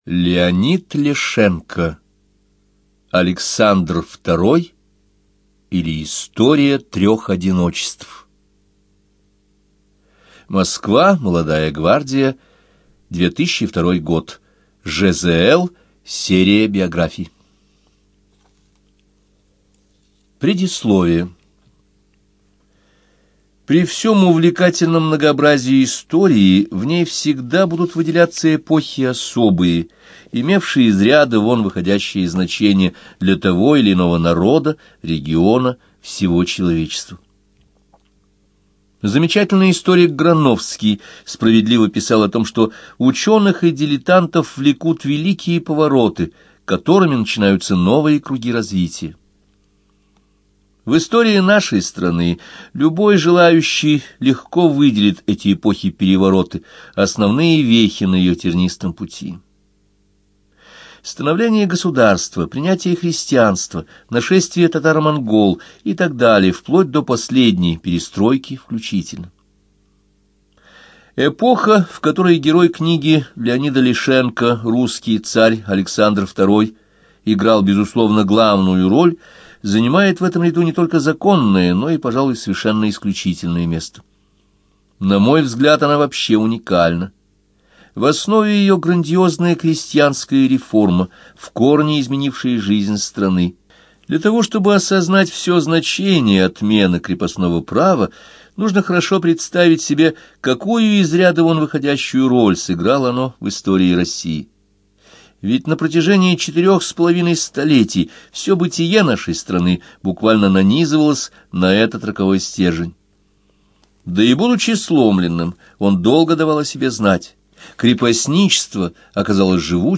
ЖанрБиографии и мемуары, Документальные фонограммы
Студия звукозаписиЛогосвос